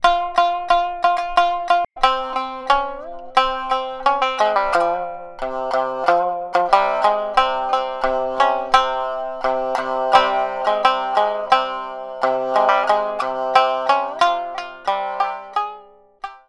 Traditional fishing folk song (Minyo) for shamisen.
• niagari tuning (C-G-C)